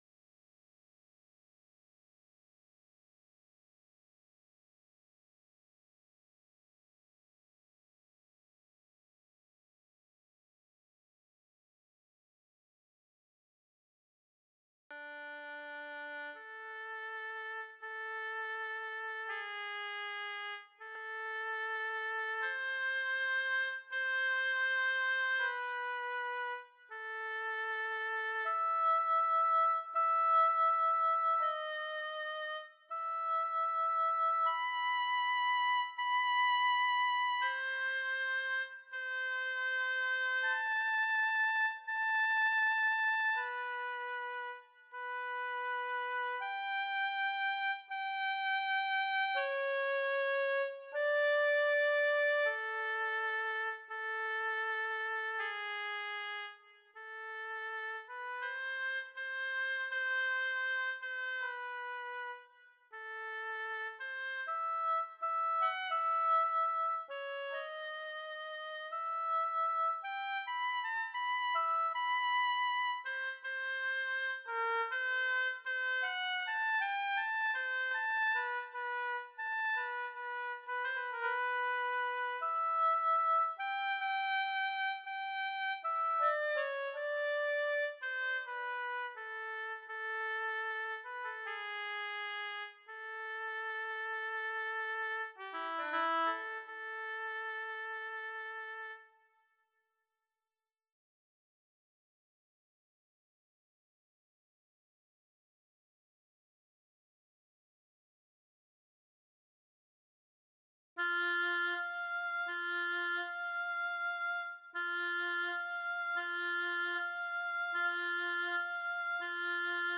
komplettes Stück in langsamem Übungs-Tempo